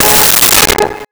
Car Horn In Parking Structure
Car Horn in Parking Structure.wav